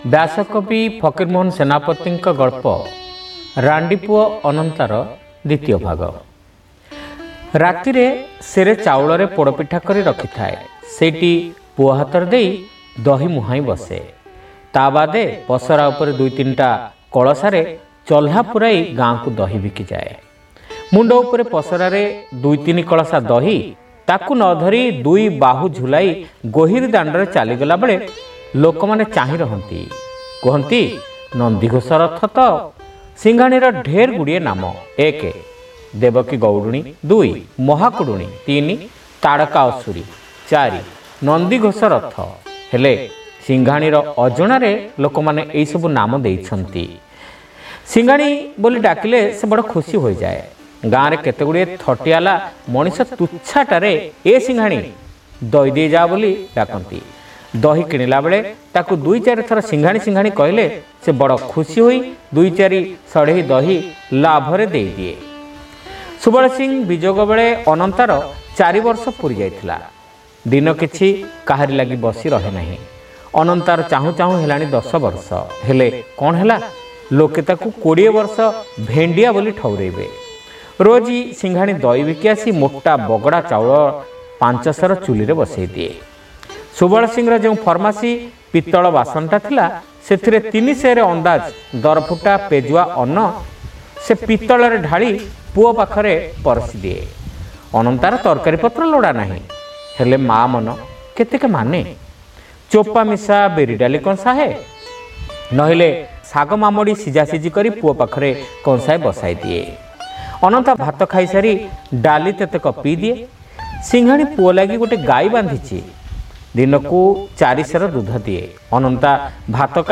ଶ୍ରାବ୍ୟ ଗଳ୍ପ : ରାଣ୍ଡିପୁଅ ଅନନ୍ତ। (ଦ୍ୱିତୀୟ ଭାଗ)